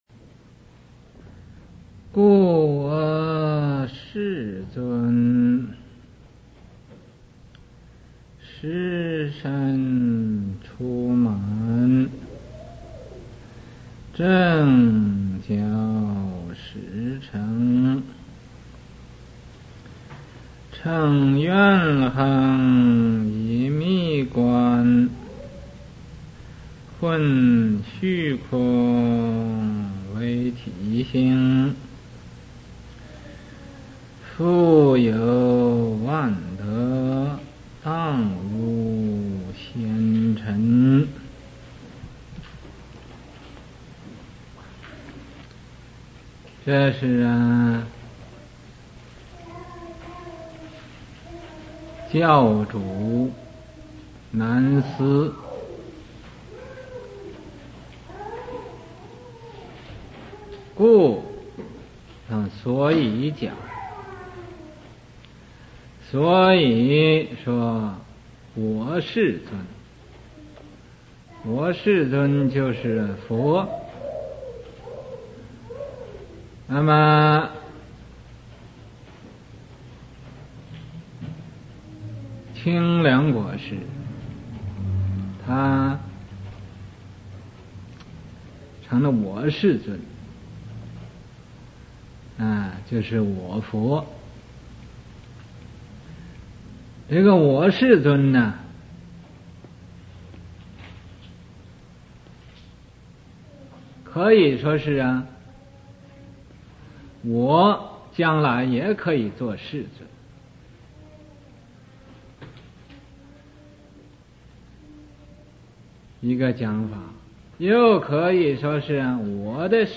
佛學講座-聲音檔